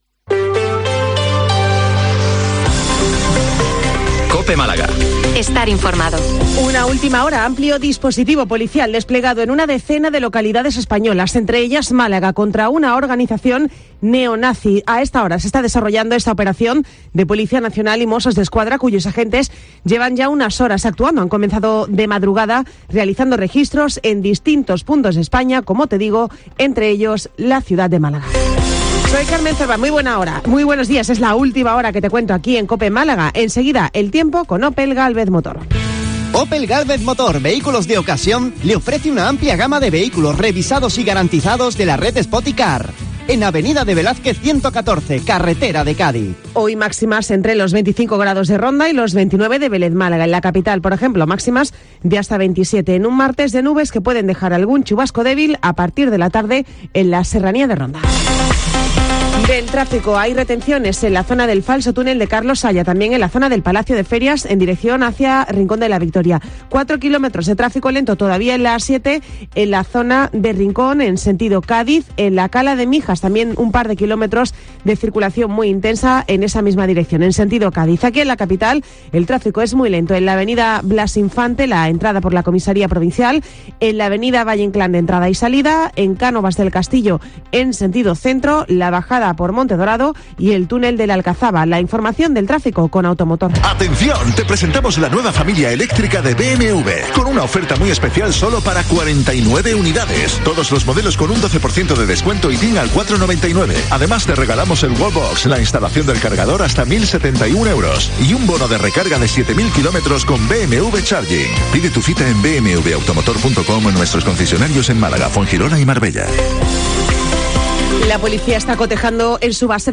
Informativo 08:24 Málaga -171023